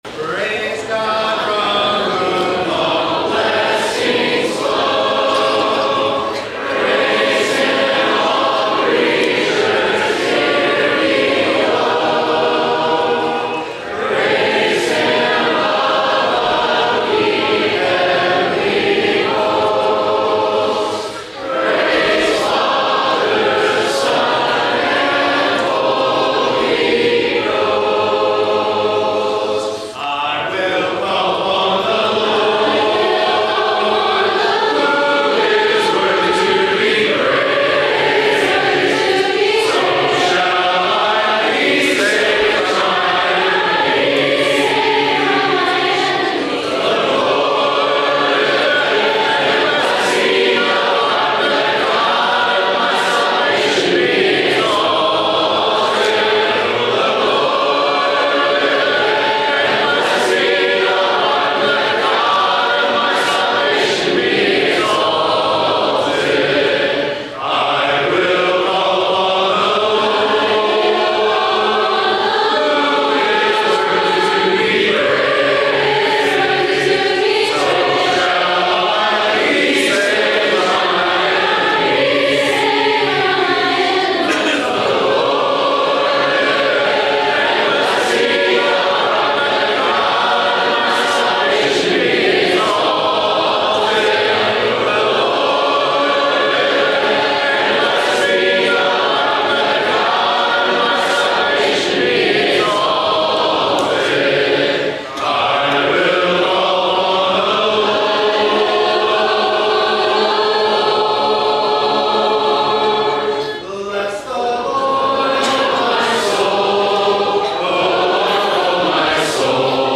Area Wide Teen Gathering – Just the Songs